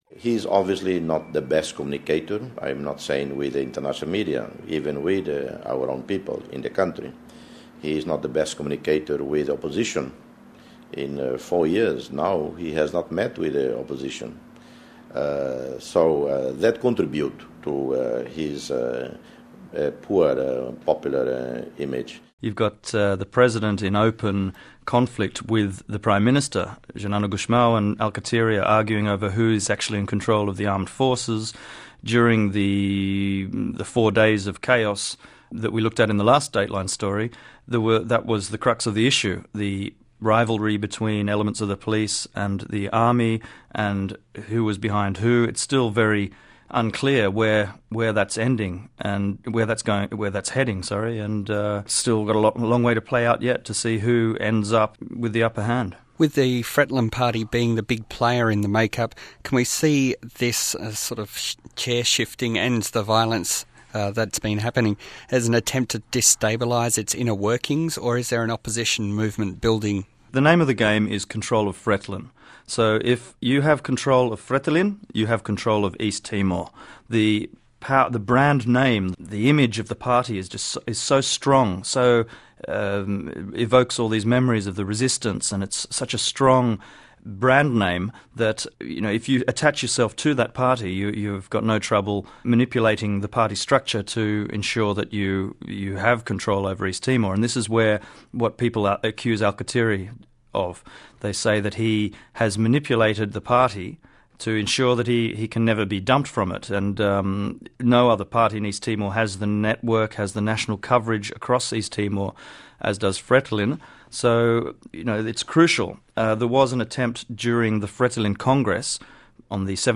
Firstly, we hear from Foreign Minister Jose Ramos Horta